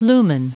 Prononciation du mot lumen en anglais (fichier audio)
Prononciation du mot : lumen